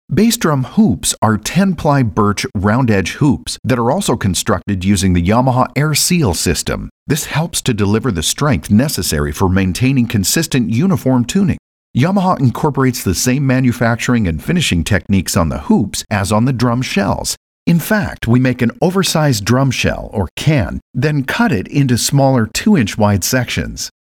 Warm, authentic, trustworthy, experienced English male voice.
Sprechprobe: Industrie (Muttersprache):